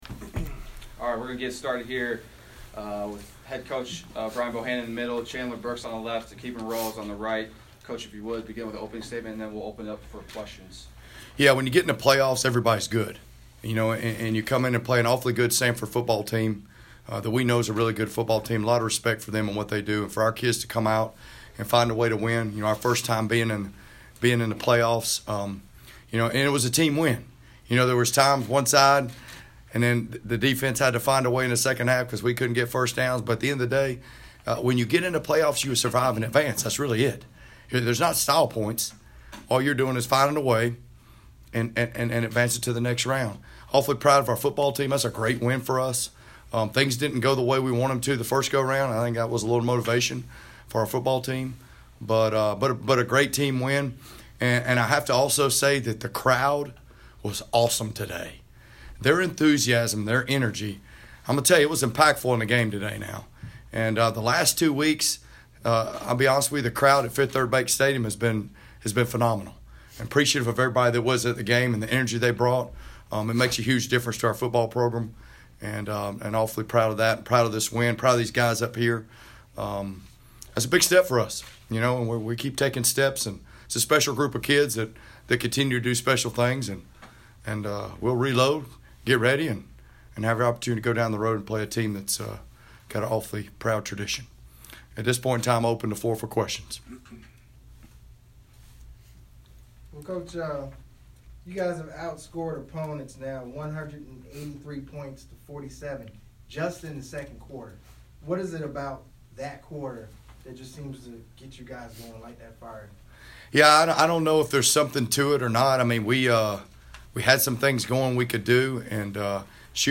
PressConference.mp3